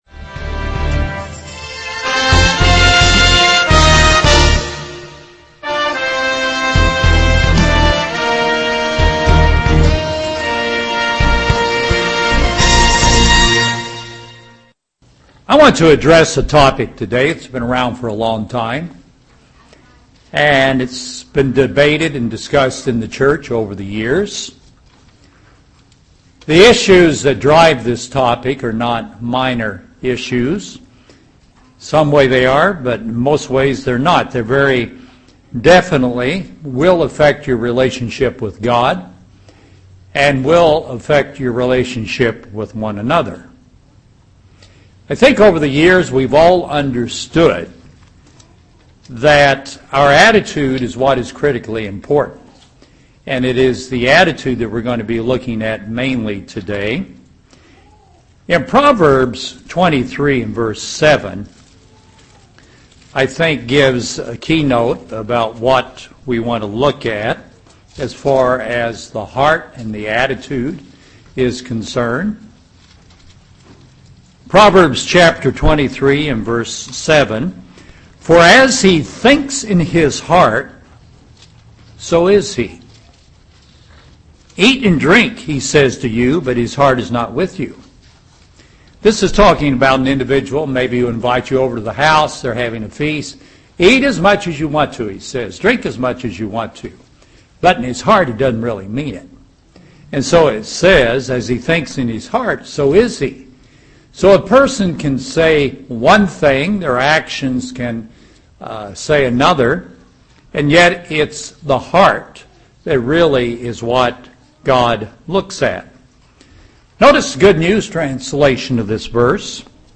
Romans 12:1-2 UCG Sermon Transcript This transcript was generated by AI and may contain errors.